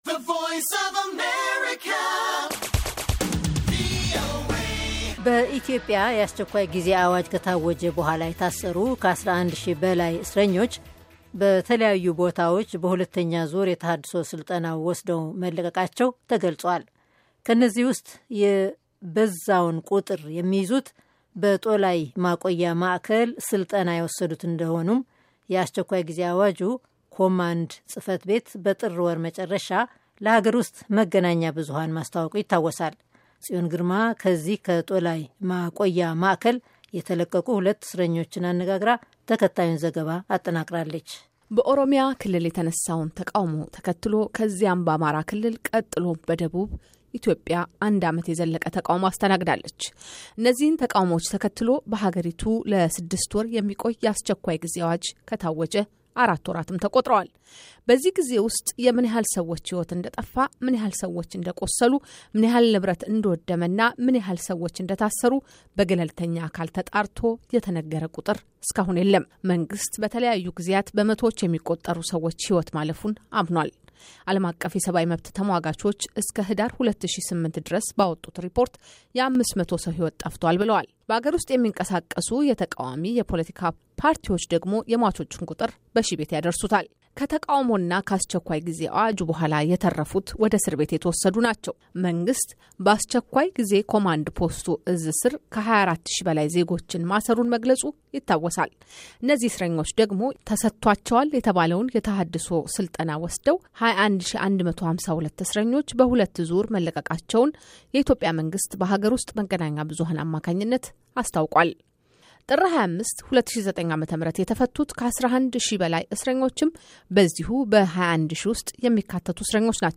ከጦላይ የተለቀቁ ሁለት እስረኞች ስለቆይታቸው ይናገራሉ